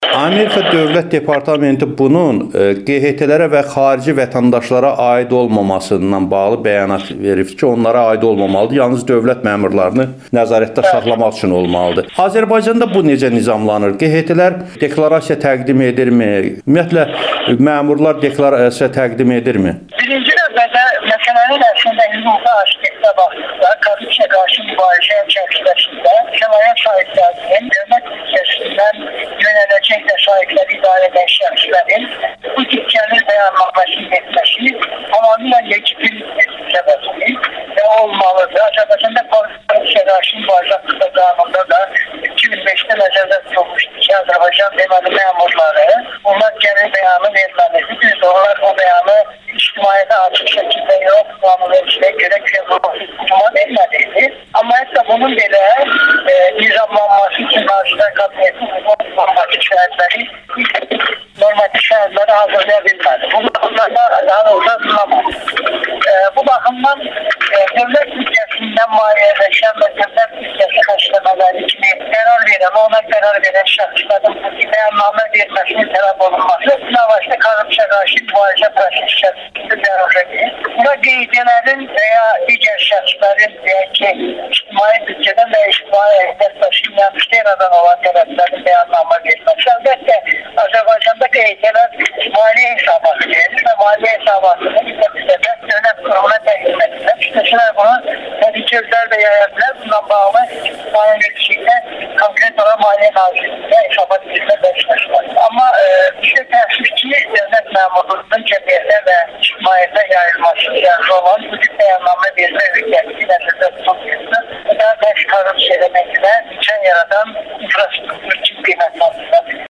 Dövlət Departamenti: Ukraynanın gəlirlərin bəyan etmə sistemi vətəndaş cəmiyyətinə təsir göstərməli deyil (Ekspert şərhi-audio müsahibə)